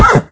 sounds / mob / horse / hit2.ogg
hit2.ogg